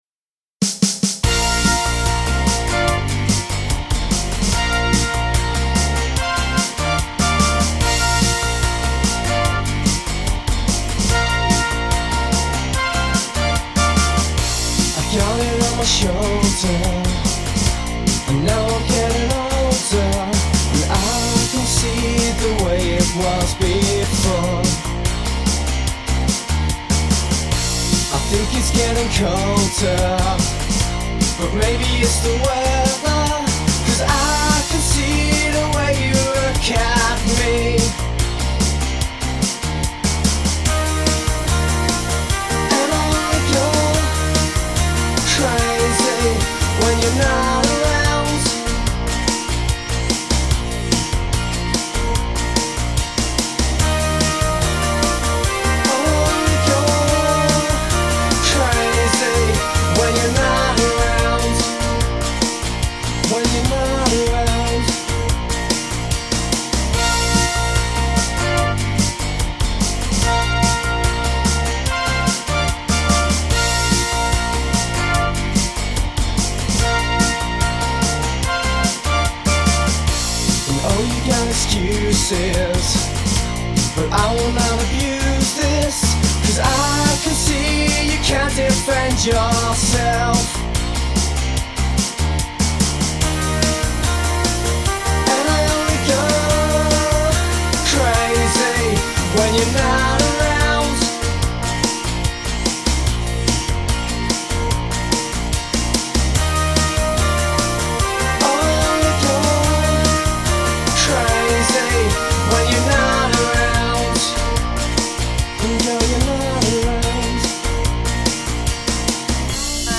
Songs - only rough demos, but they'll give you the idea: